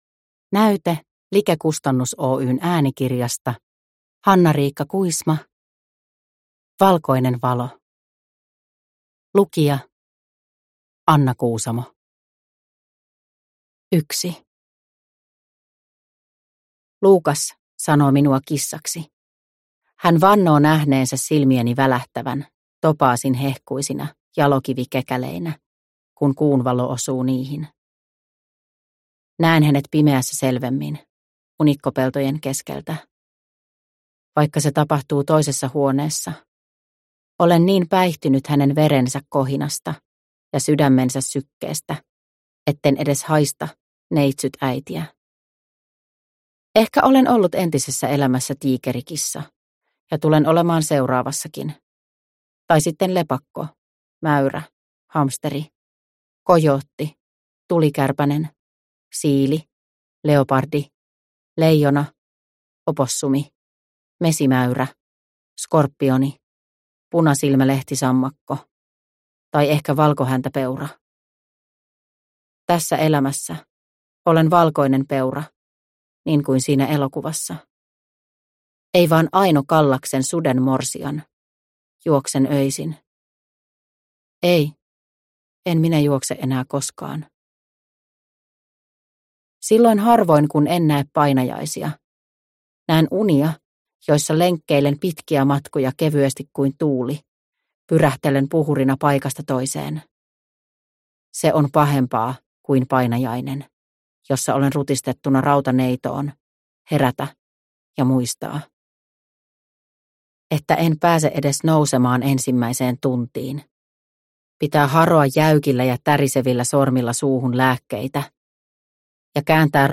Valkoinen valo – Ljudbok – Laddas ner